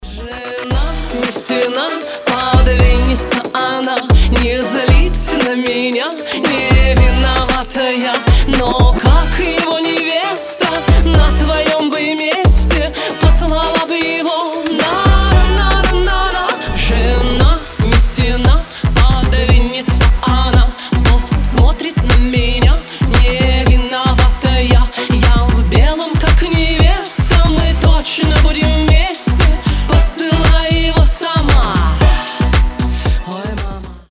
• Качество: 320, Stereo
шуточные